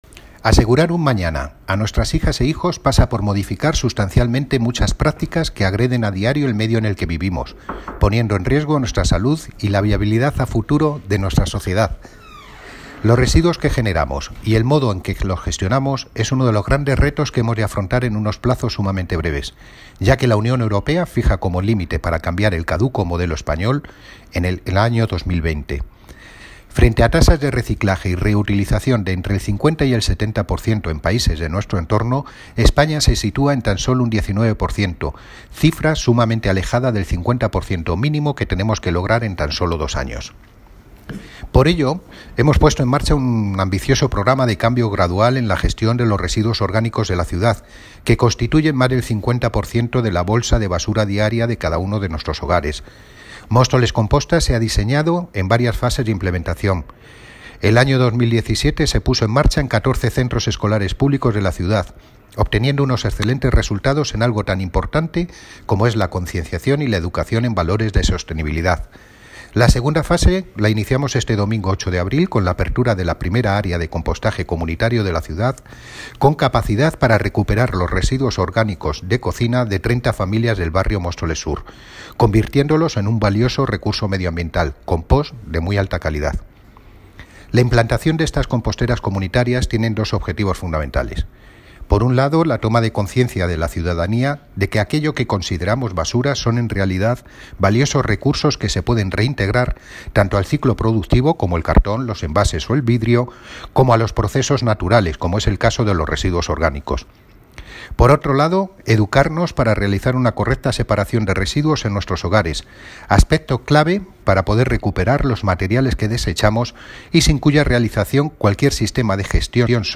Audio - Miguel Ángel Ortega (Concejal de Medio Ambiente, Parques y Jardines y Limpieza Viaria) Sobre Taller Compostaje